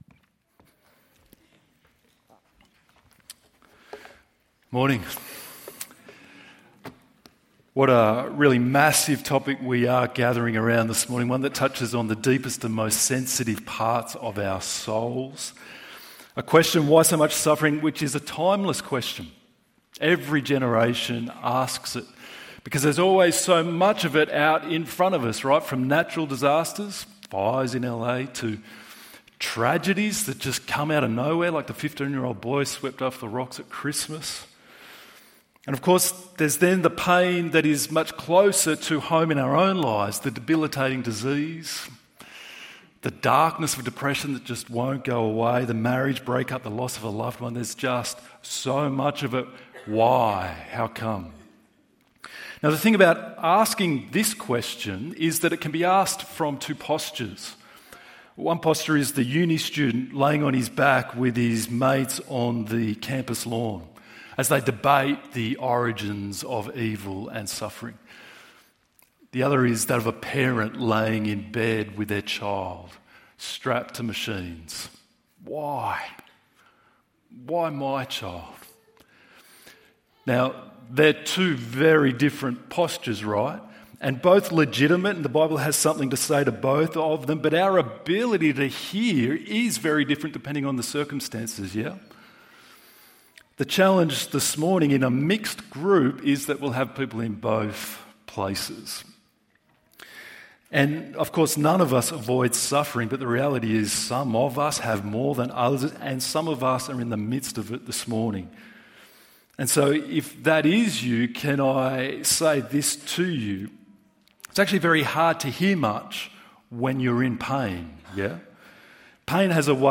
Why is there so much suffering in the world? ~ EV Church Sermons Podcast